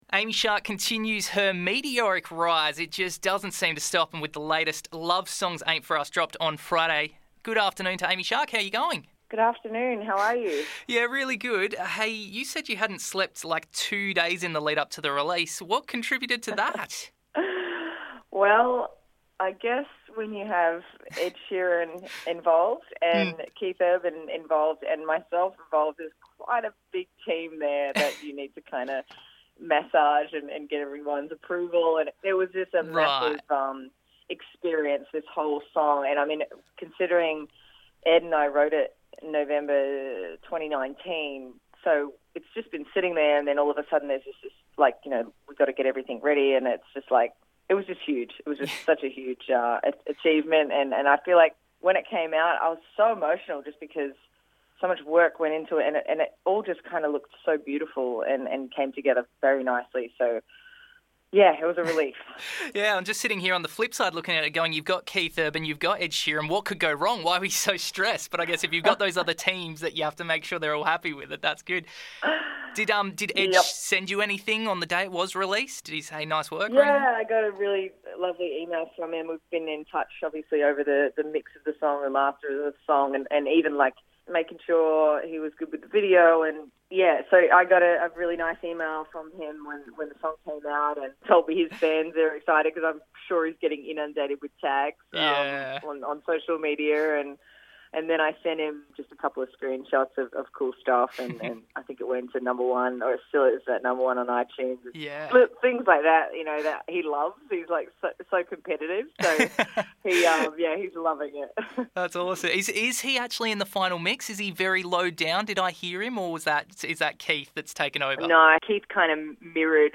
Amy Shark Interview